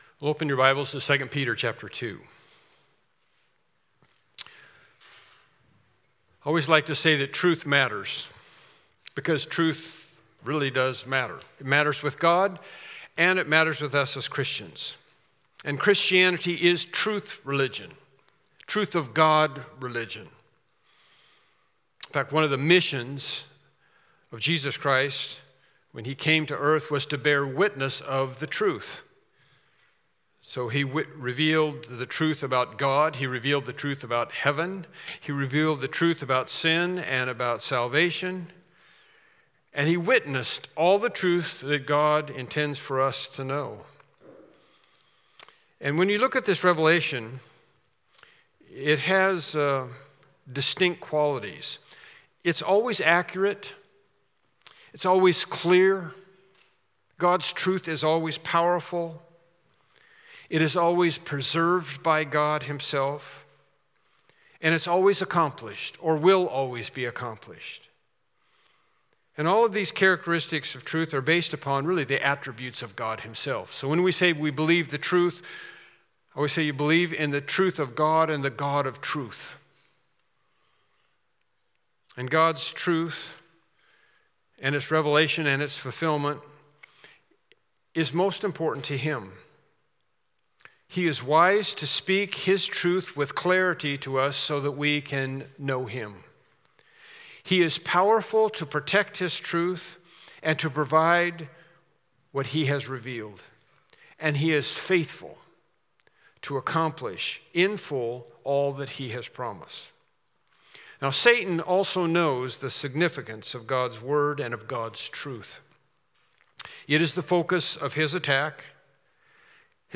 2 Peter 2:17-19 Service Type: Morning Worship Service Topics: False Teachers